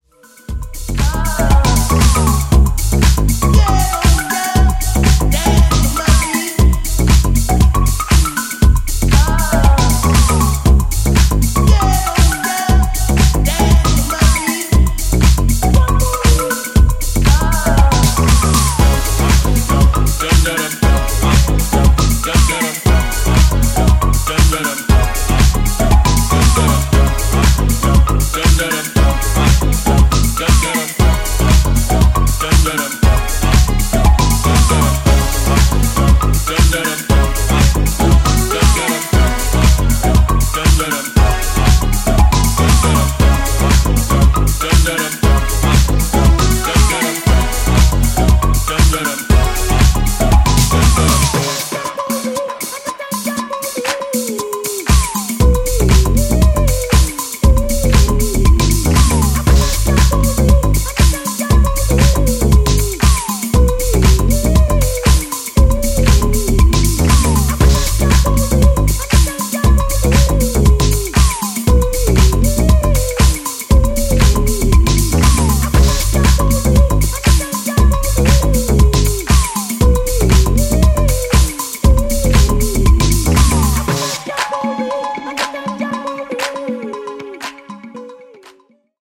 dancefloor edits